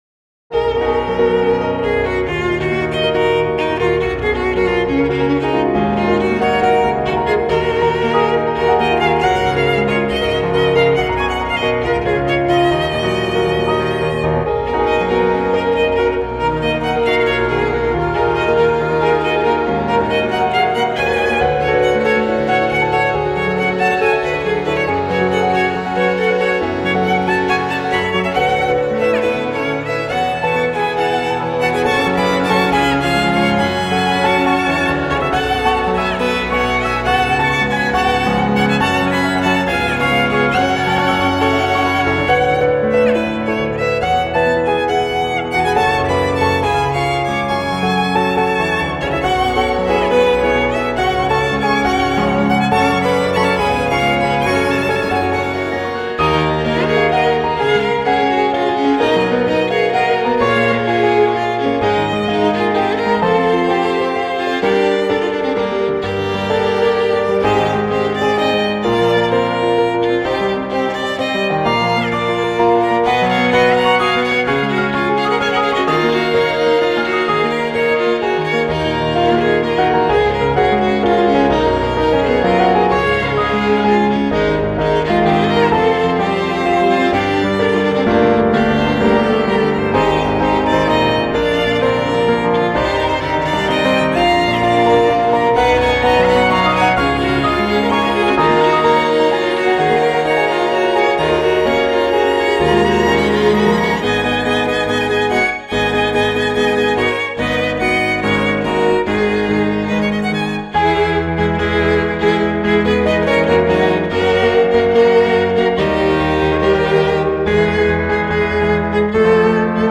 ・Main Violin+Piano
1st Violin
2nd Violin
Viola
Cello
Piano